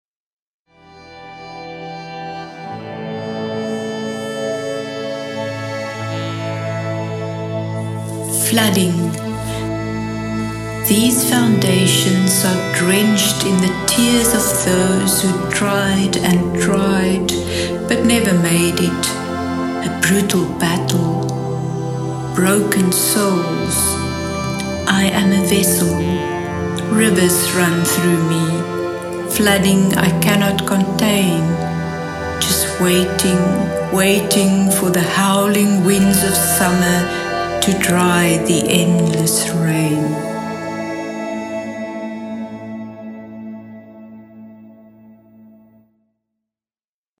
A collection of textured beats featuring artists’ contributions to Ons Klyntji 2023.